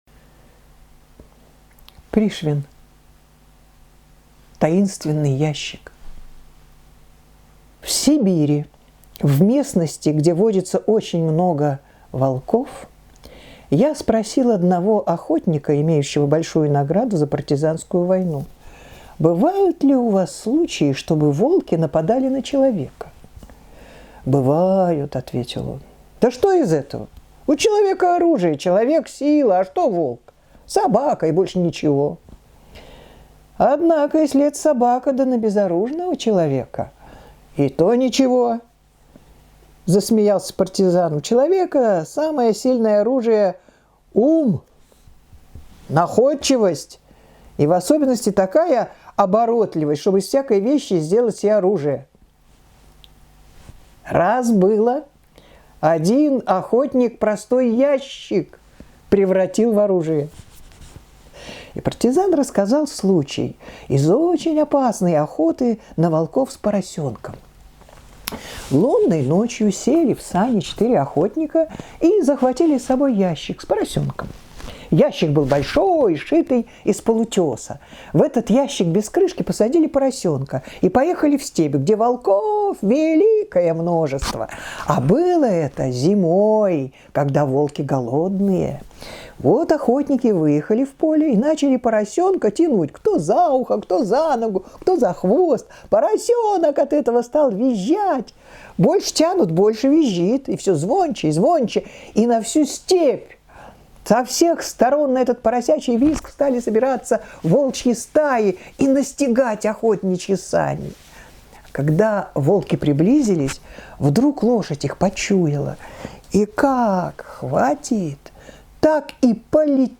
Таинственный ящик - аудио рассказ Пришвина М.М. Рассказ про то, как смекалка помогает человеку спастись от волков, даже без ружья.